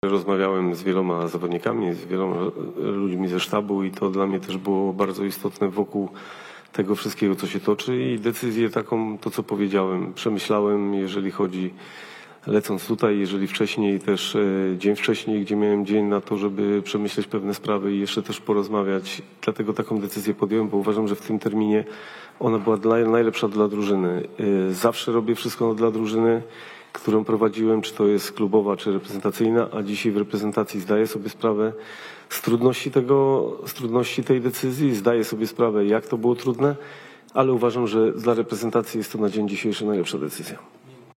Argumentacja Michała Probierza podczas konferencji przedmeczowej z Finlandią.